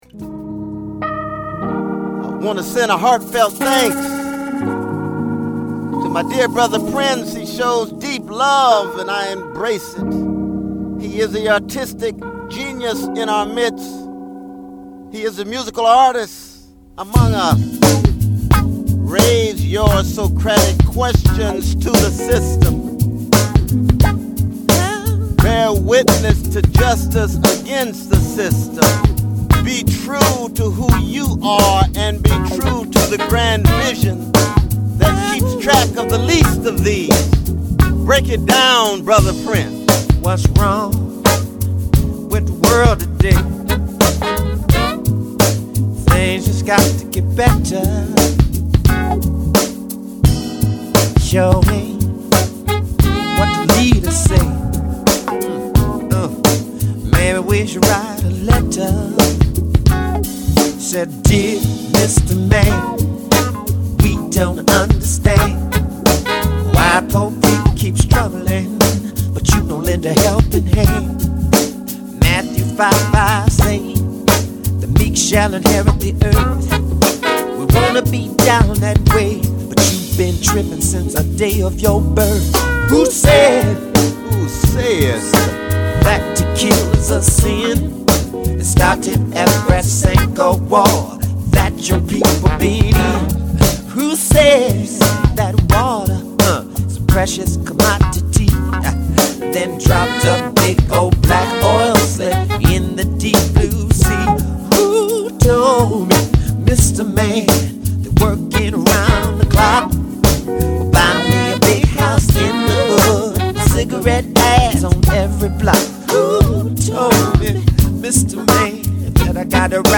If only all protest music were this funky.